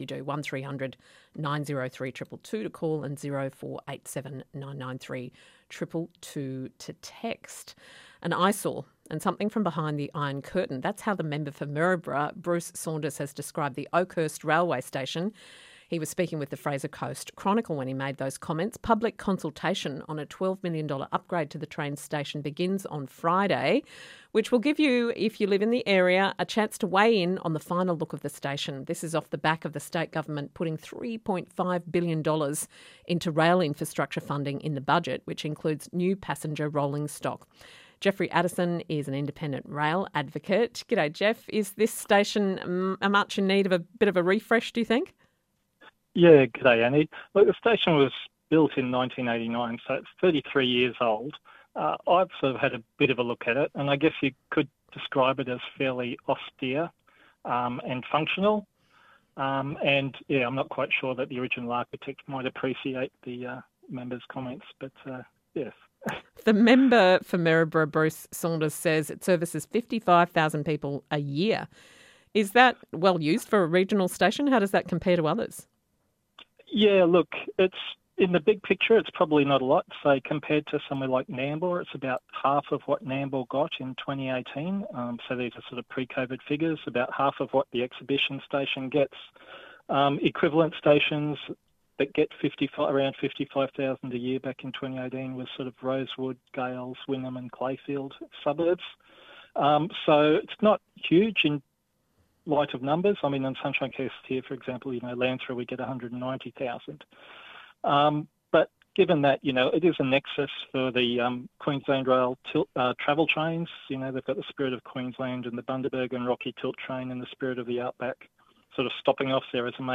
Interview ABC South Queensland Drive